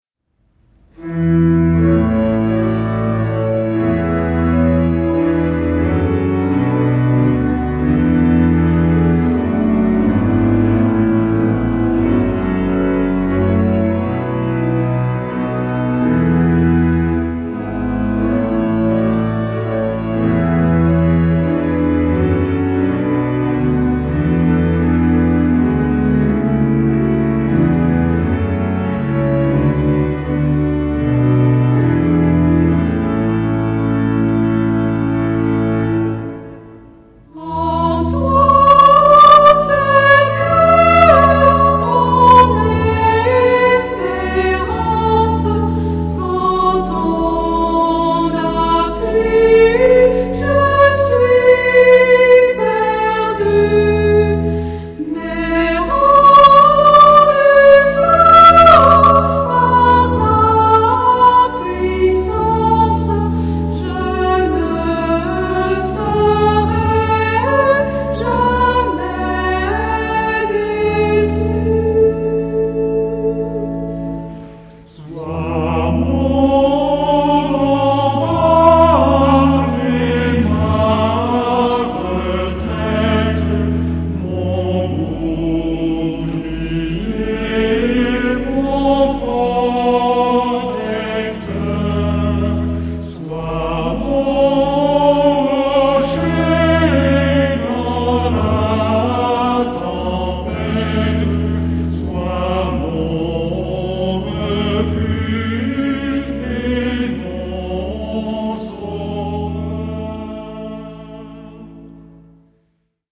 Genre-Style-Forme : Hymne (sacré)
Caractère de la pièce : recueilli ; calme ; confiant
Type de choeur : SATB  (4 voix mixtes )
Tonalité : la majeur